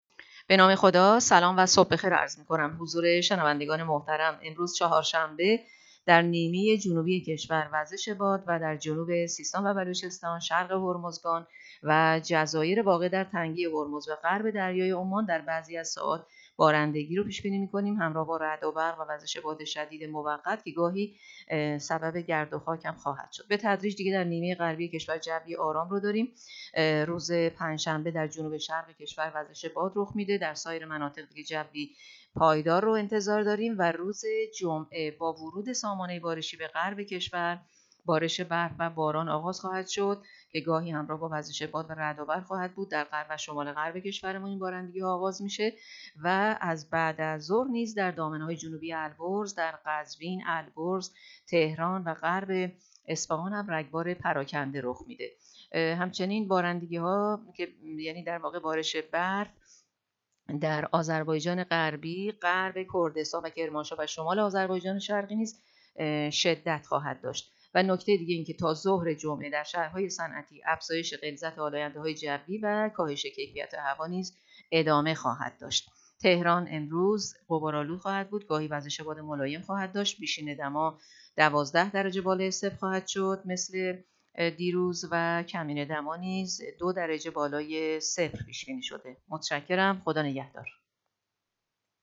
گزارش رادیو اینترنتی پایگاه‌ خبری از آخرین وضعیت آب‌وهوای۵ دی؛